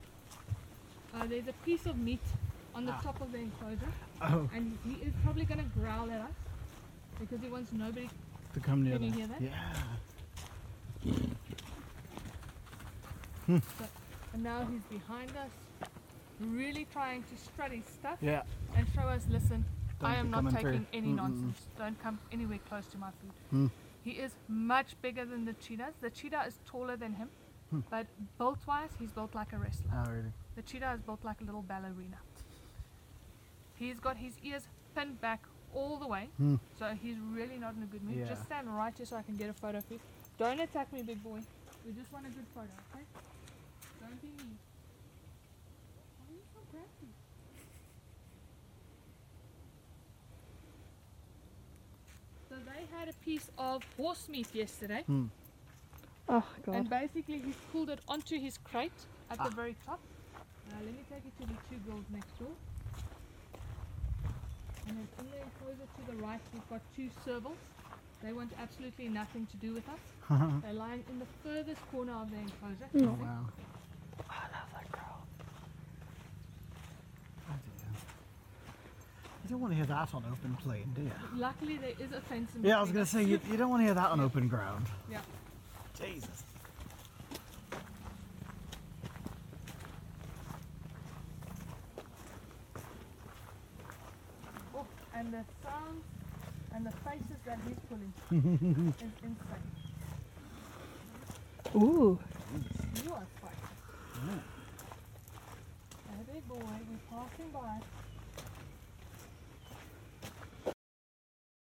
If it ever enters your head to get between a leopard and his food...this growl might possibly persuade you to reconsider that notion!
Aww they sound so cute.